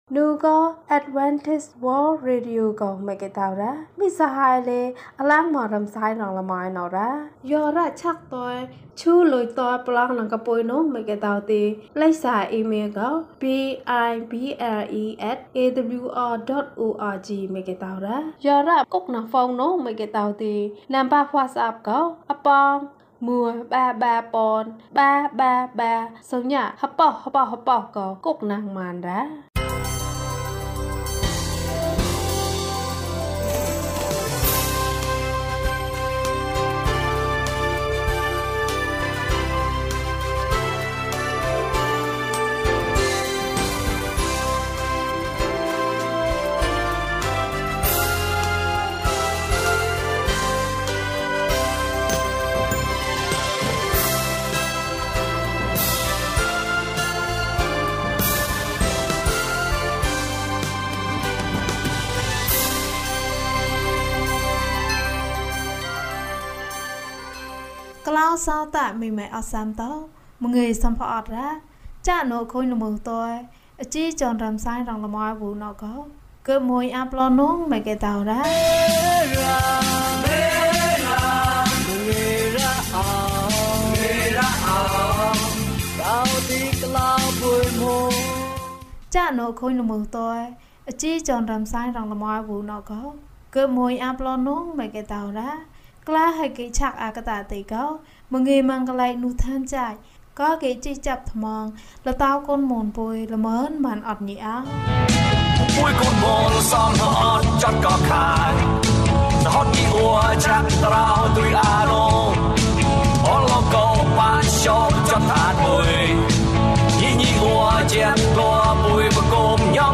အခြားဘုရားသခင်စကားများကို မျှဝေပါ။၀၁ ကျန်းမာခြင်းအကြောင်းအရာ။ ဓမ္မသီချင်း။ တရားဒေသနာ။